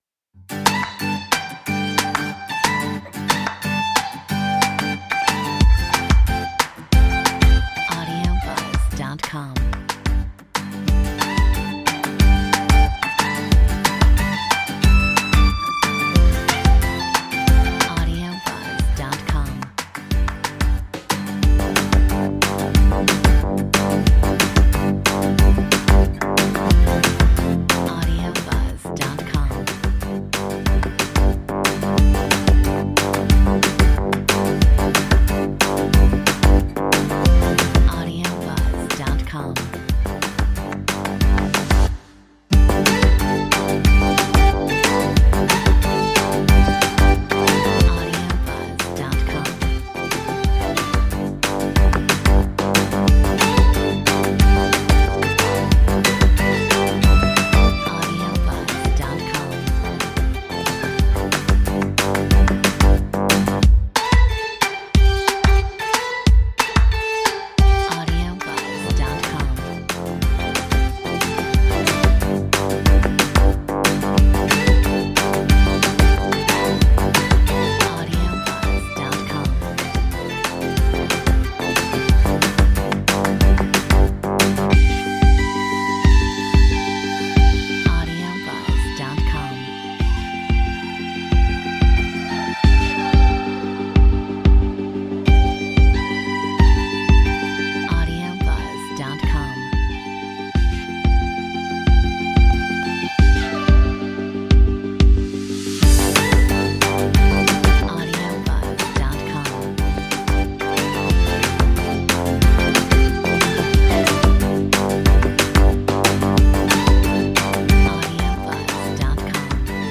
Metronome 91